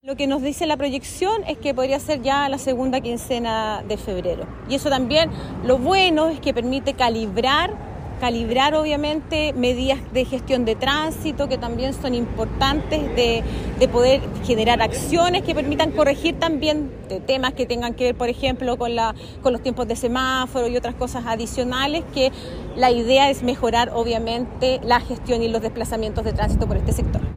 La seremi de Vivienda, Claudia Toledo, señaló que la segunda quincena de febrero, el nuevo paso podrá ser utilizado.